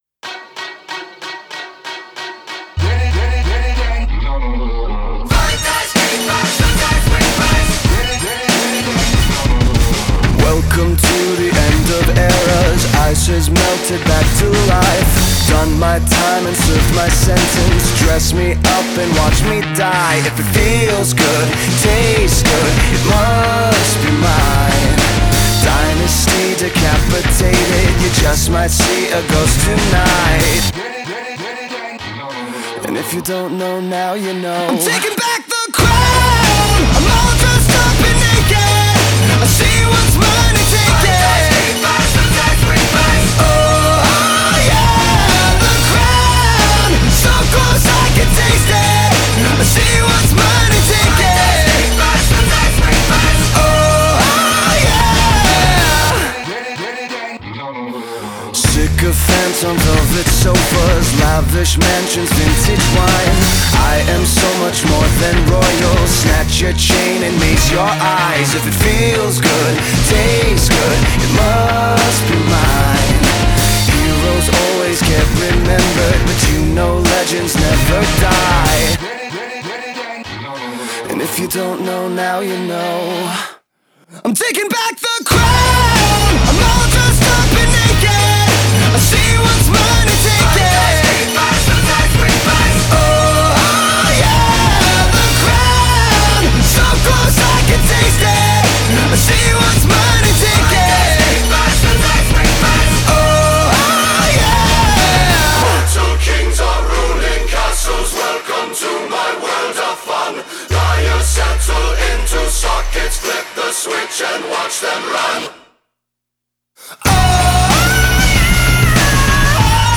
Genre: Alternative Rock, Pop Rock